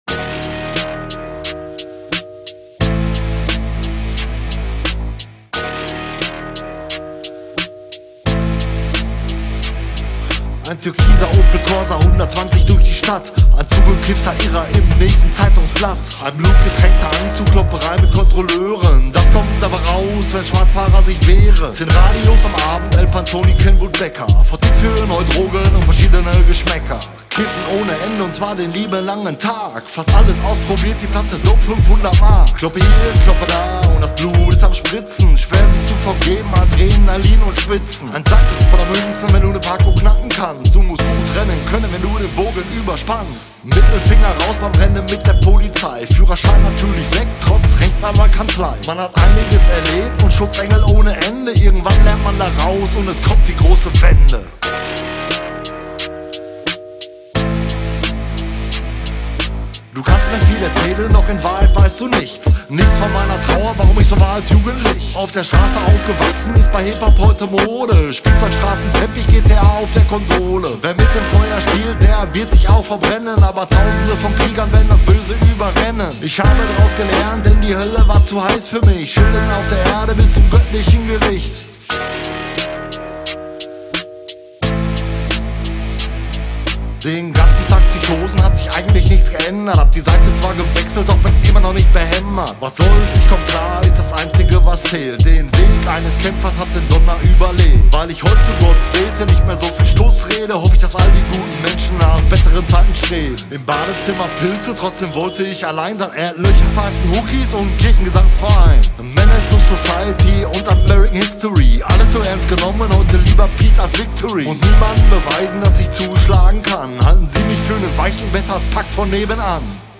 Spielzeugstraßen-Teppich - HipHop
Insgesamt ist das ein gesunder Gegenentwurf zu überproduzierten Plastikbeats.
Mein Fokus lag auf einen etwas helleren und höhenlastigeren Sound, dadurch wirds insgesamt etwas dreckiger und gröber. Die Vocals könnte man noch lauter machen, ist geschmackssache.